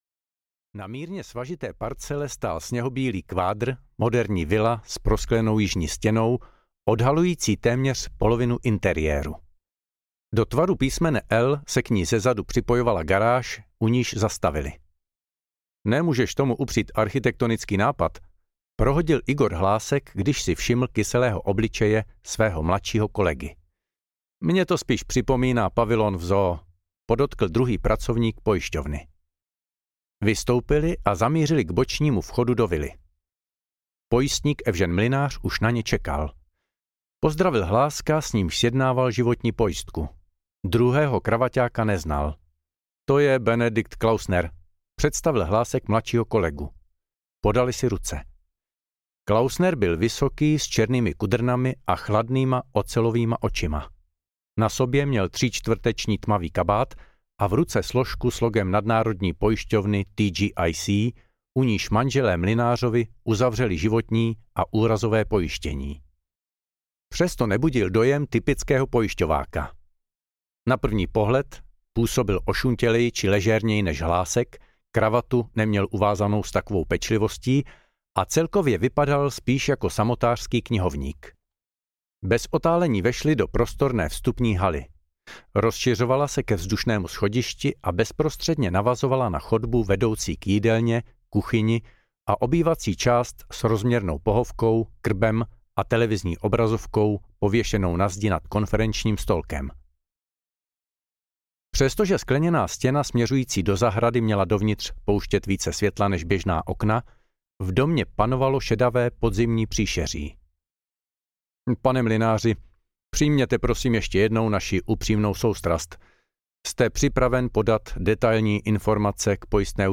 Pozdravy záhrobí audiokniha
Ukázka z knihy